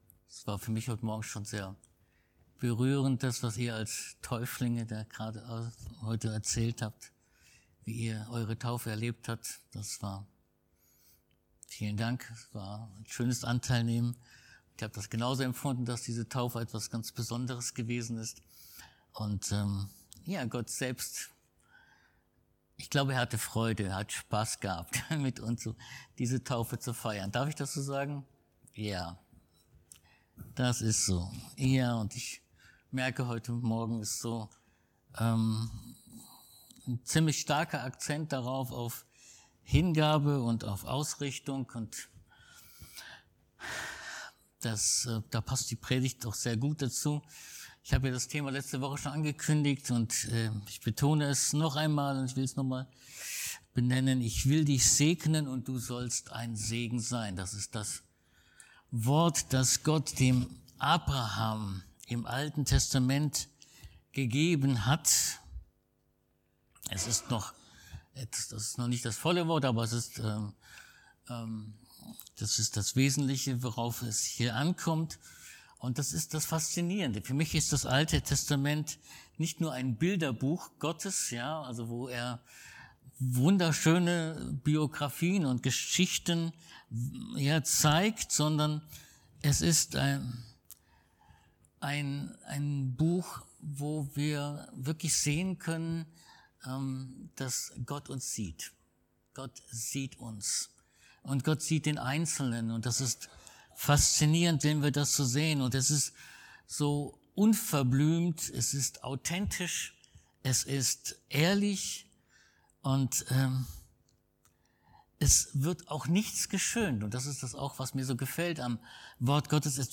Mose 30,19; 1.Chr 4, 9-10; Ps 23, Lukas 7, 47 Dienstart: Predigt Gott möchte seine Kinder segnen und sie lehren, um manche Segnungen auch zu ringen.